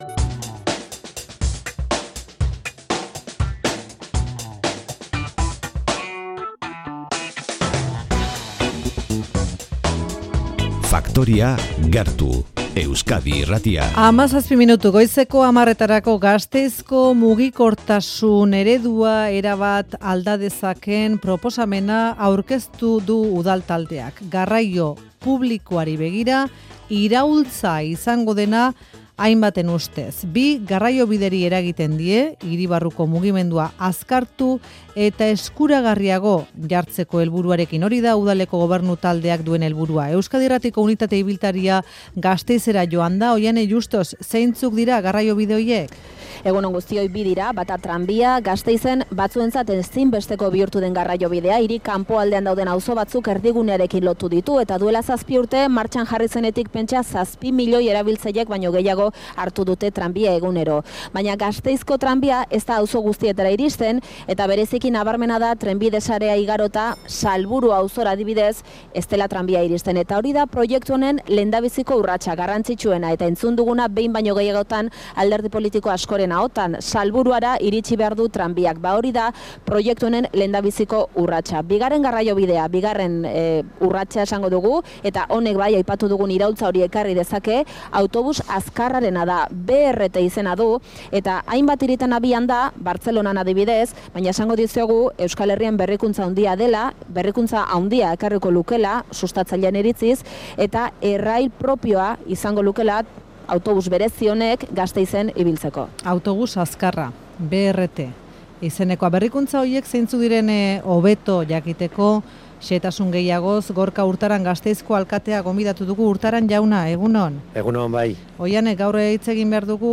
BRT autobus elektrikoen garraio sistema proiektuari buruz hitz egin dugu Gorka Urtaran alkatearekin, egitasmoa zehatzago jakiteko asmoz.